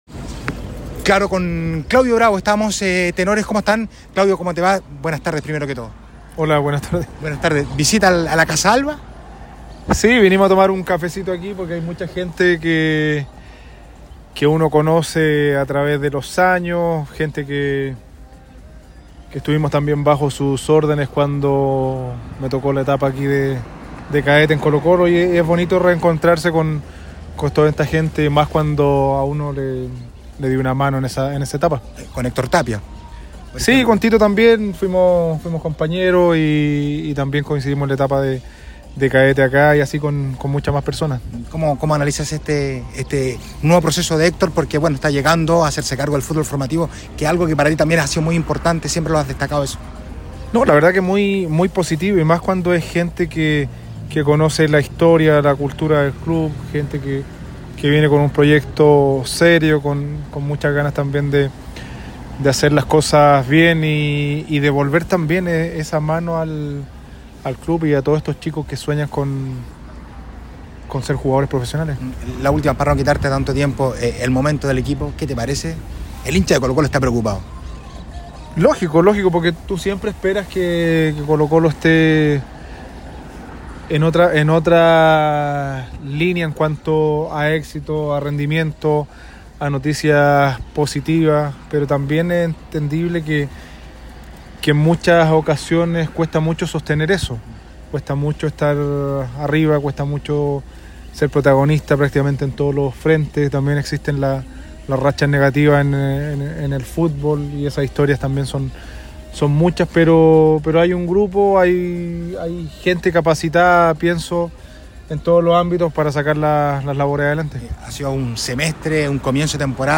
En conversación con ADN Deportes, el exportero comentó el presente de los albos y defendió la decisión del club de no buscar otro arquero